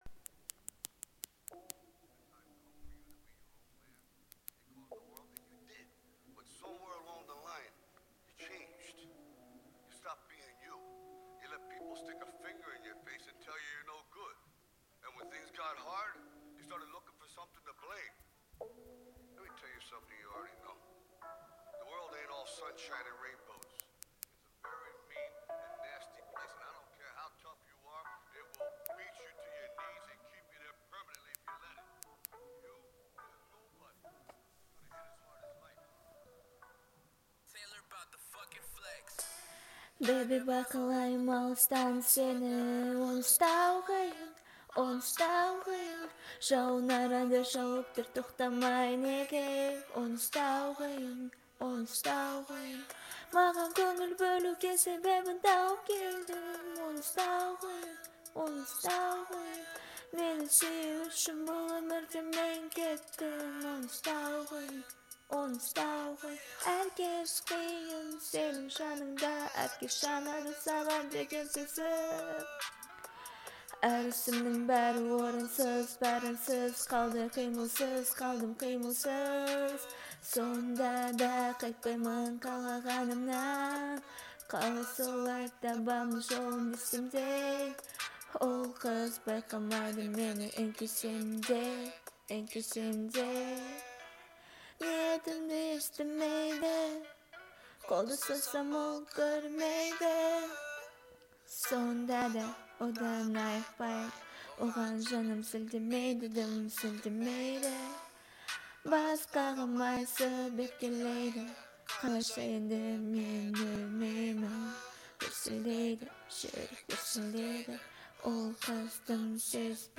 яркая и эмоциональная композиция
традиционные инструменты